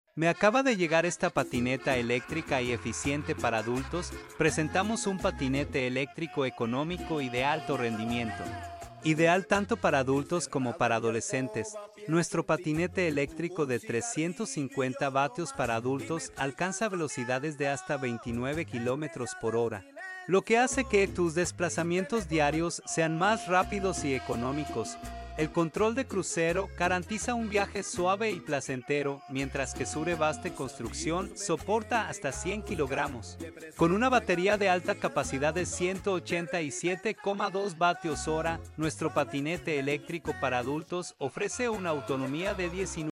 AOPO Electric Scooter for Adults, sound effects free download